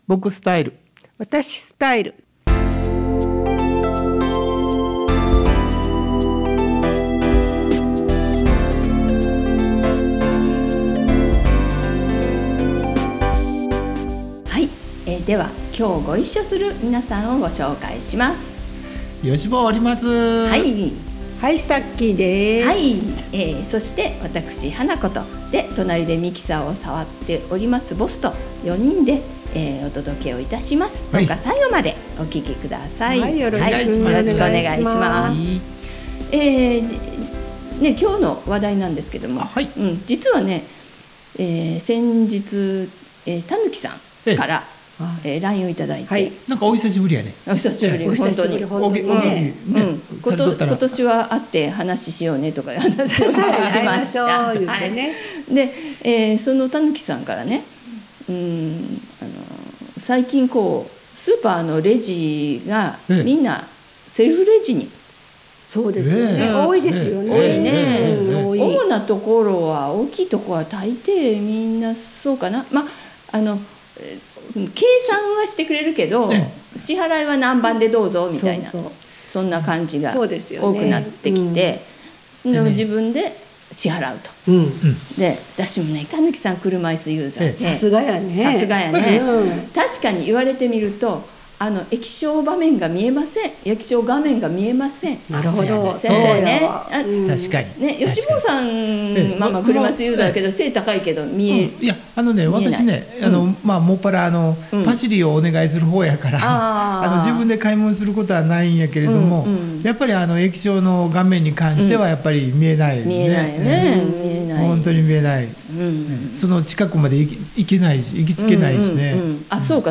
この番組はマイノリティーの声なき声をお届けすることで、心のバリアフリー化を目指し「多様性を認め合う社会を作ろう」という相生市発のマイノリティーラジオです。